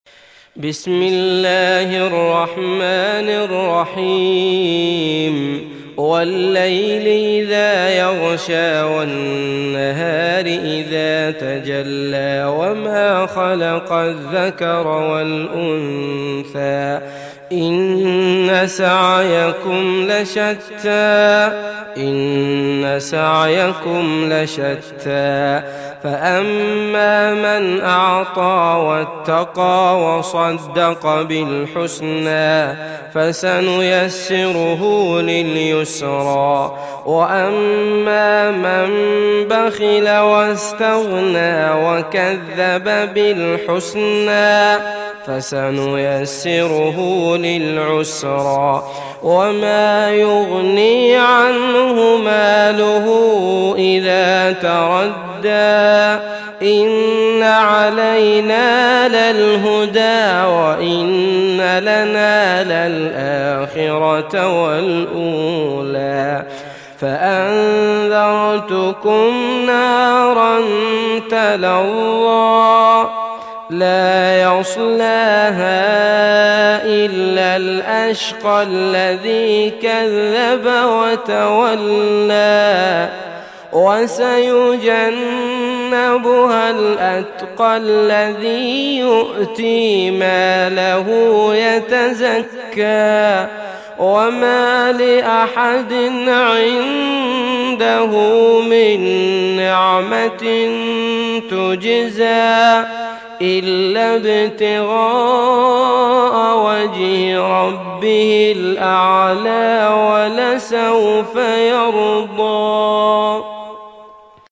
Sourate Al Layl Télécharger mp3 Abdullah Al Matrood Riwayat Hafs an Assim, Téléchargez le Coran et écoutez les liens directs complets mp3